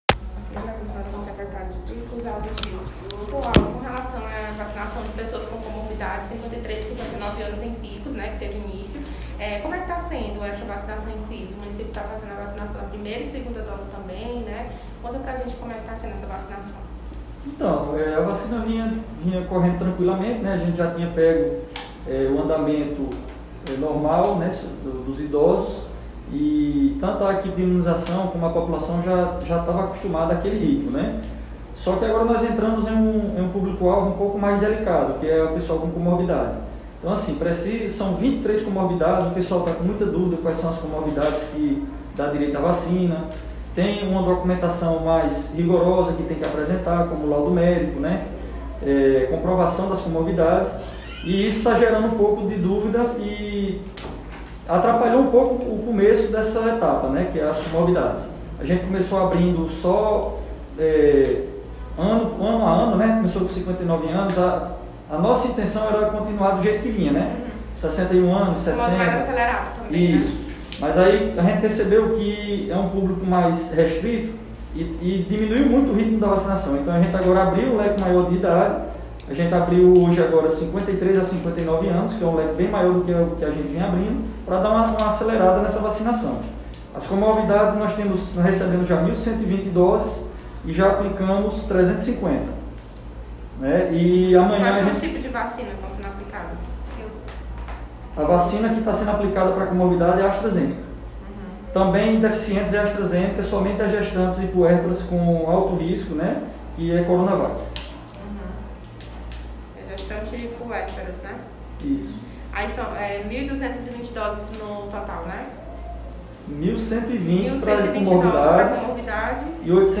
O Secretário de Saúde, Aldo Gil, explica que a vacinação agora é voltada para um público alvo mais delicado, ou seja, as pessoas portadoras de comorbidades.